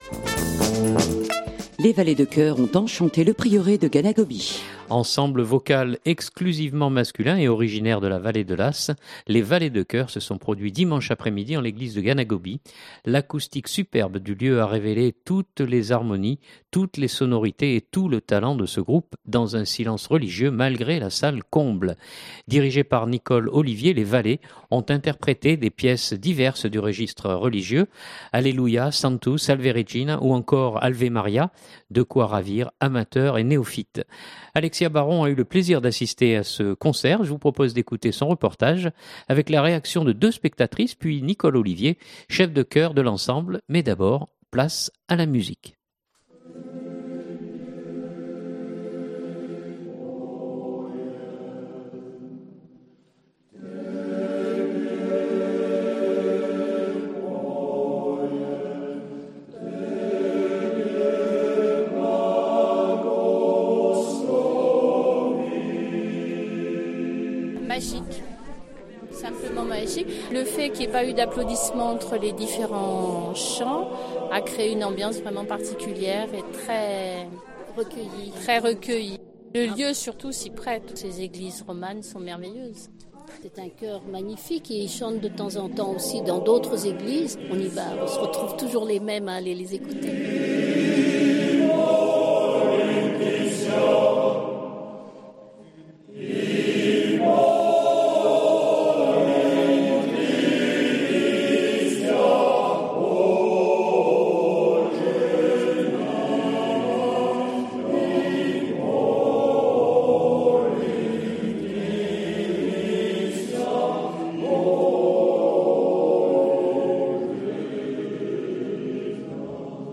Ensemble vocal exclusivement masculin et originaire de la vallée de l’Asse, les Valets de chœur, se sont produits dimanche après-midi en l’église de Ganagobie. L’acoustique superbe du lieu a révélé toutes les harmonies, toutes les sonorités et tout le talent de ce groupe dans un silence religieux malgré la salle comble.
Mais d’abord, place à la musique ! écouter ou télécharger Durée : 03'33" Les valets de choeur.mp3 (2.44 Mo)